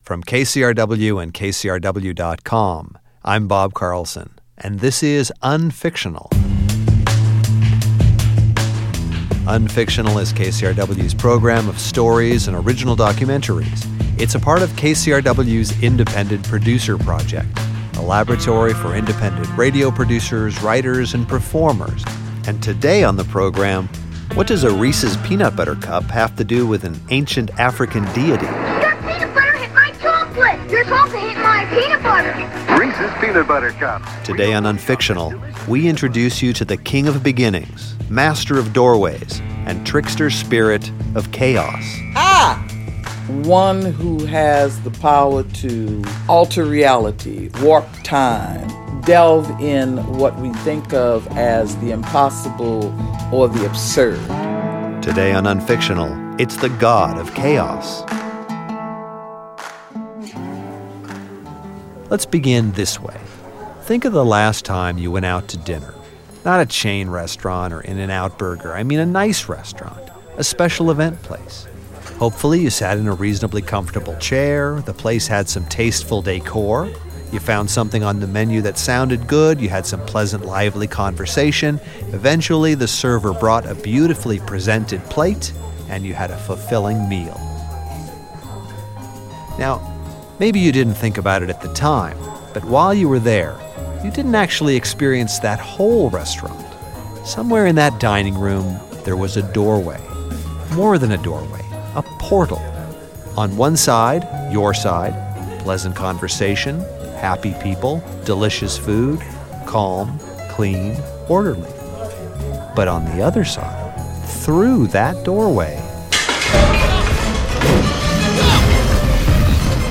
Take a trippy, intimate and lyrical journey to meet Elegba, king of beginnings, master of doorways, and trickster spirit of chaos.